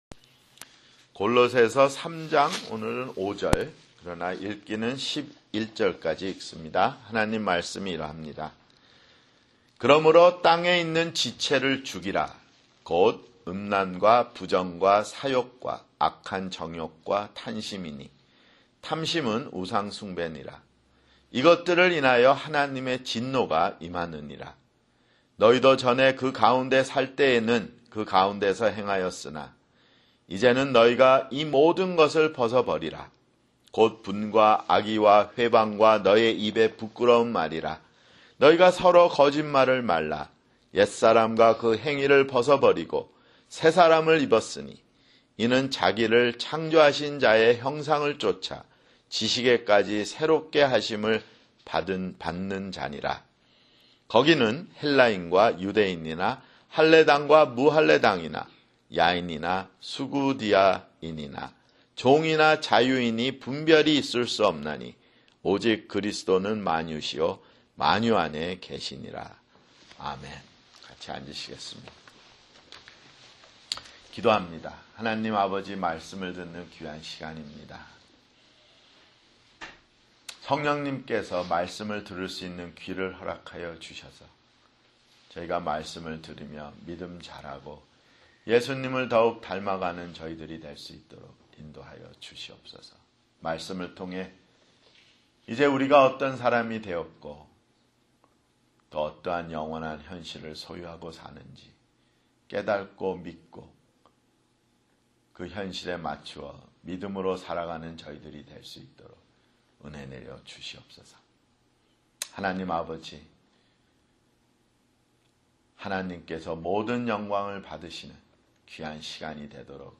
[주일설교] 골로새서 (58)